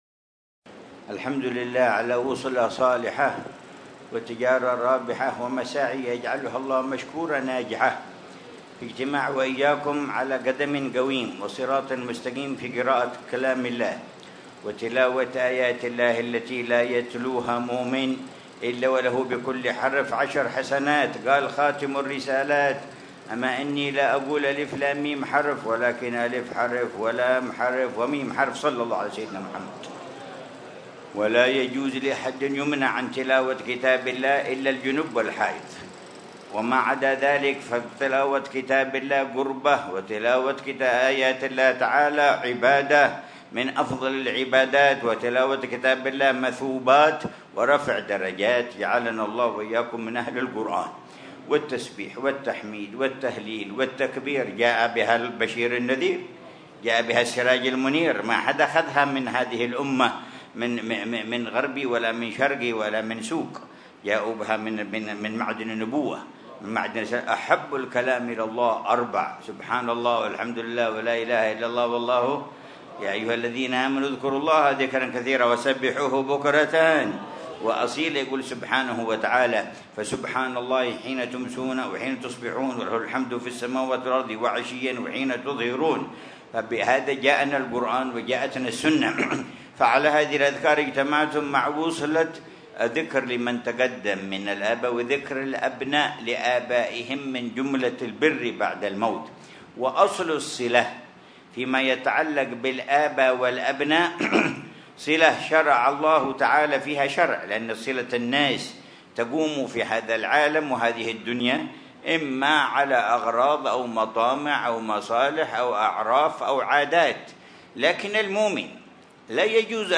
مذاكرة الحبيب عمر بن محمد بن حفيظ في حولية السيد علي بن صالح الكاف في منطقة الهجرين، ظهر الأحد 13 ربيع الثاني 1447هـ بعنوان: